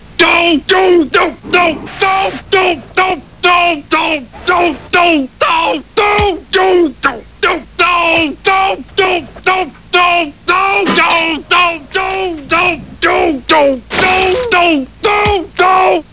Homer Simpson realizing that he messed up.
32dohs.wav